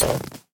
repair_wolf3.ogg